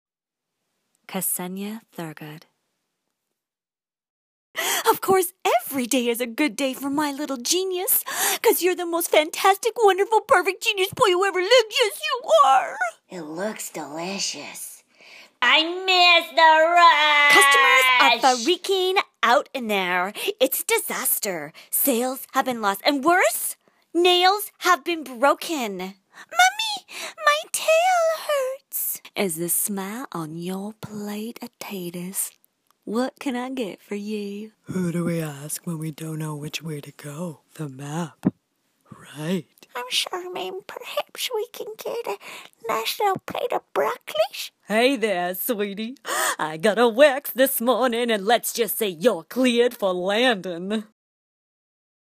Animation - ANG